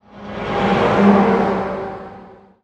car10.wav